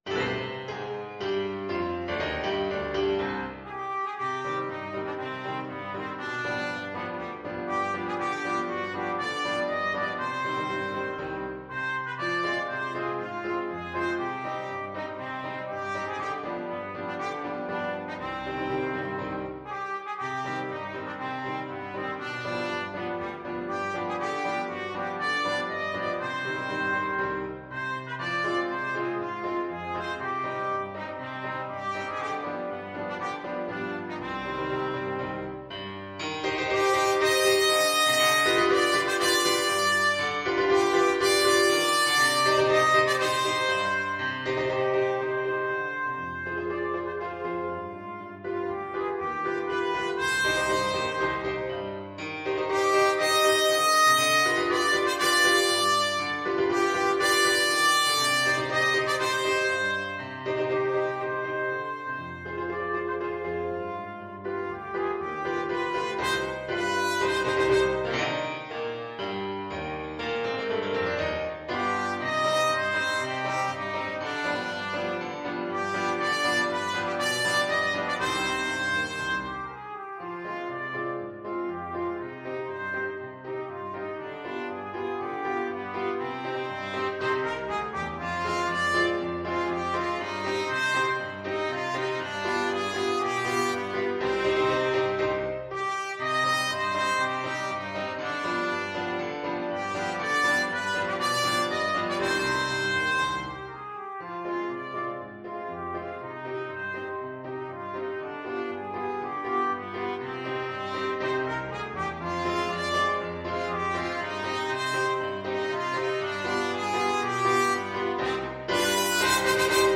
2/2 (View more 2/2 Music)
Allegro = c.120 (View more music marked Allegro)
Classical (View more Classical Trumpet Music)